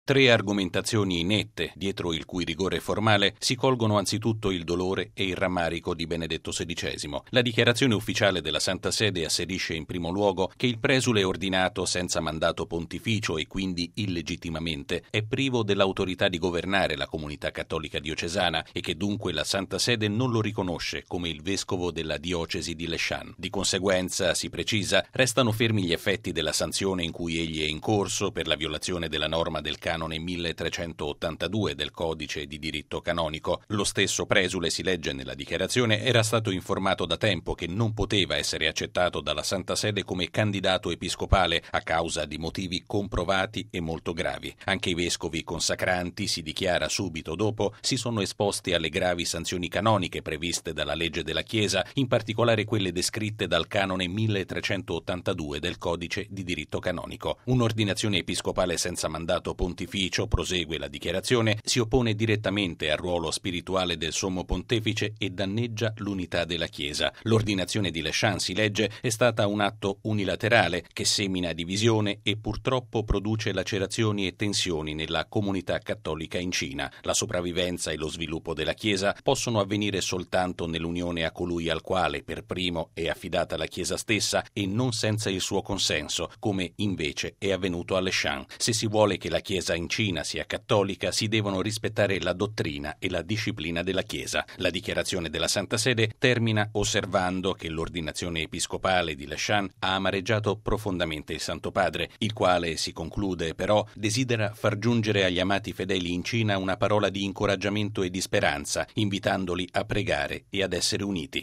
È racchiusa in tre punti sostanziali la reazione della Santa Sede alla notizia dell’ordinazione episcopale in Cina, conferita senza il mandato apostolico lo scorso 29 giugno al sacerdote Paolo Lei Shiyin. I particolari nel servizio